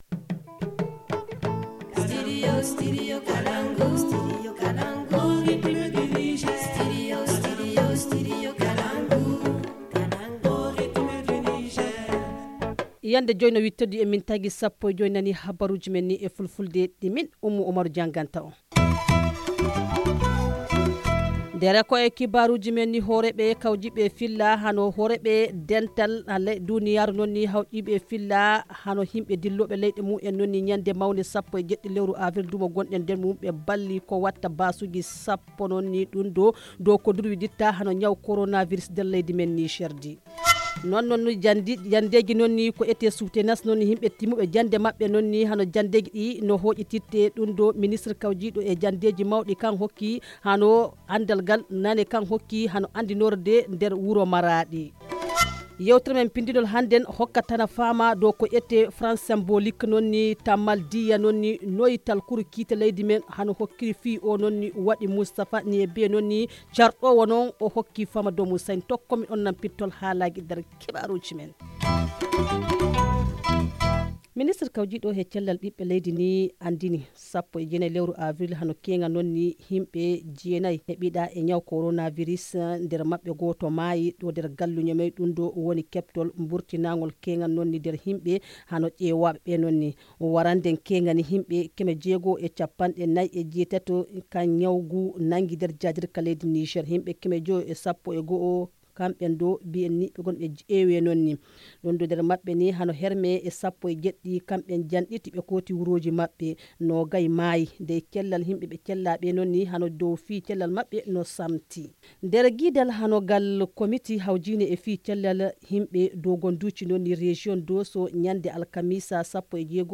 Le journal du 20 avril 2020 - Studio Kalangou - Au rythme du Niger